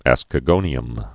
(ăskə-gōnē-əm)